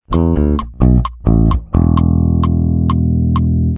🔊Feel Good Inc Bass Tab 3👇🏻
Bien, no hay mucho que decir de esta última parte de la tablatura, una resolución hacia la nota Mi, la cual, parece que deja colgado todo…pero no, luego llega el estribillo el cual es muy melódico, por cierto, en este estribillo no hay bajo en la versión de estudio.
Feel-Good-Inc-riff-3.mp3